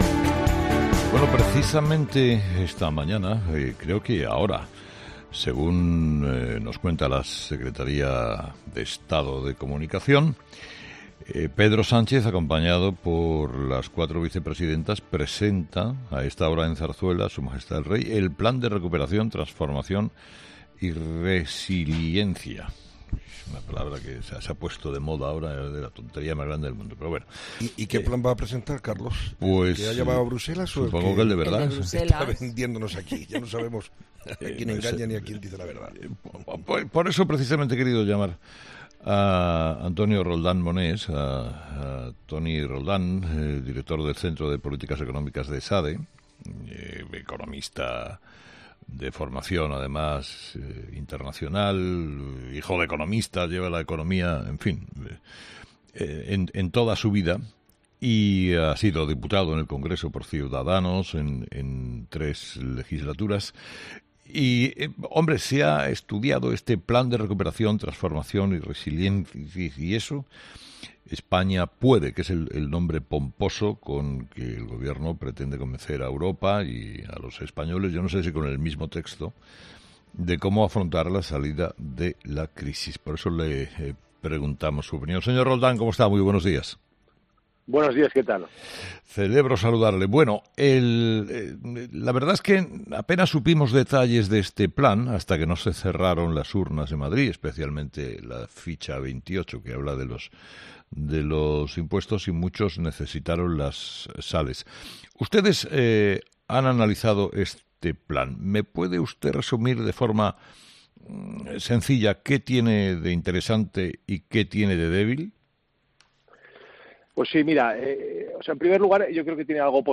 Toni Roldán , economista y exdiputado de Ciudadanos, ha sido entrevistado este viernes en 'Herrera en COPE' a propósito del Plan de Recuperación que el presidente del Gobierno, Pedro Sánchez , le ha presentado al Rey Felipe VI .